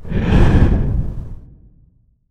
exhale.wav